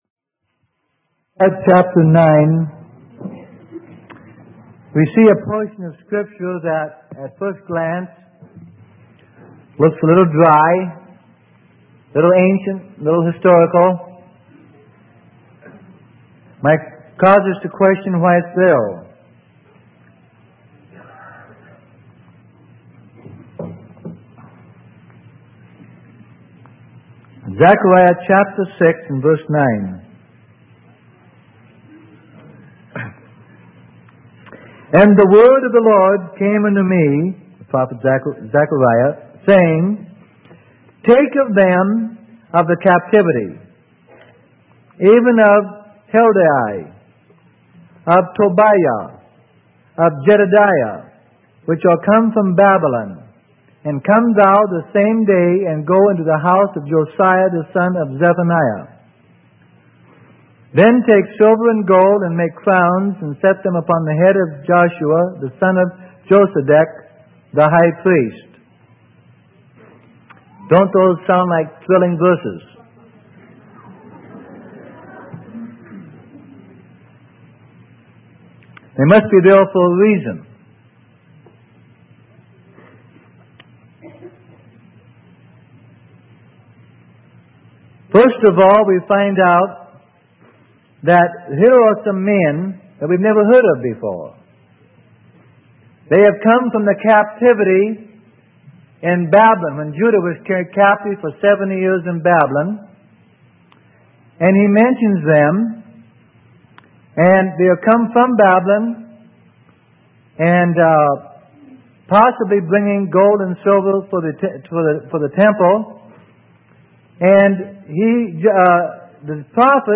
Sermon: Gleanings from Zechariah 6:9-15 - Freely Given Online Library